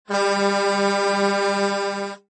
LKW Horn Klingelton Kostenlos
Klingelton LKW Horn
Kategorien Soundeffekte